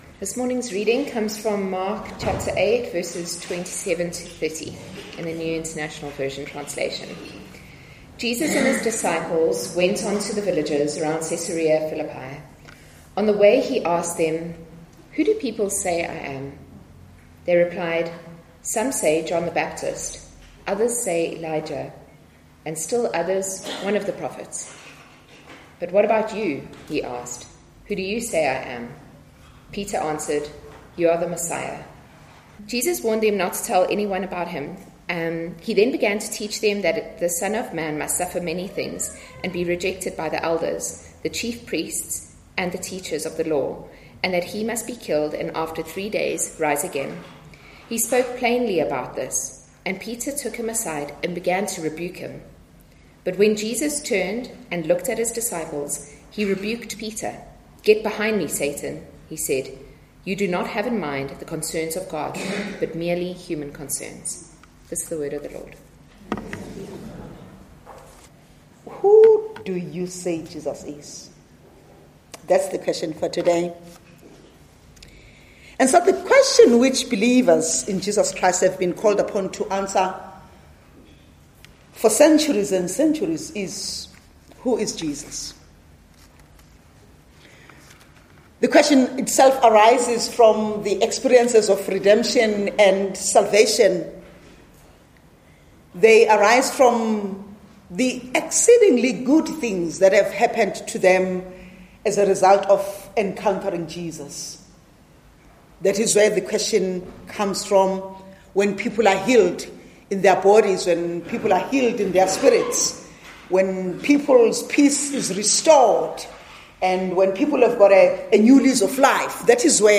Trinity Methodist Church Sermons The Crucified God - Who Do You Say That I Am?